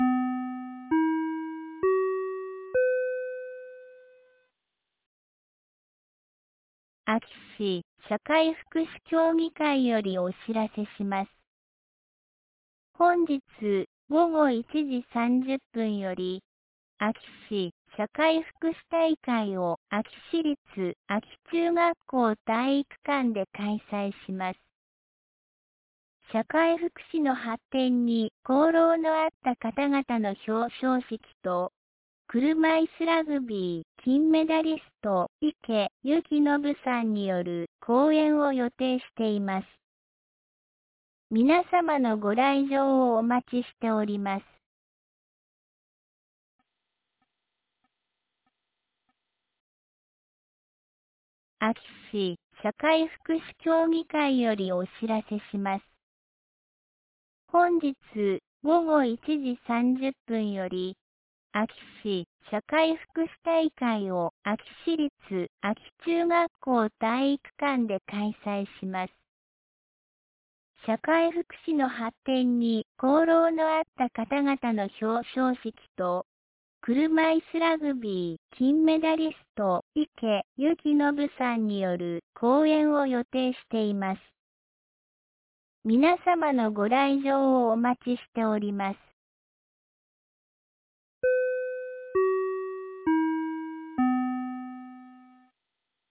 2025年02月22日 10時01分に、安芸市より全地区へ放送がありました。